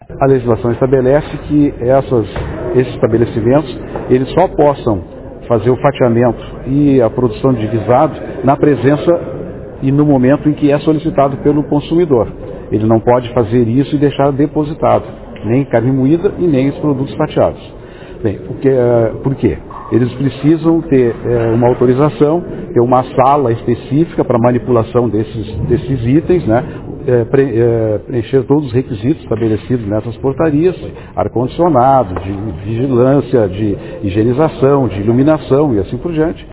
O Secretário Estadual da Saúde, João Gabbardo dos Reis, destaca que os estabelecimentos terão que possuir uma sala específica para a manipulação dos itens: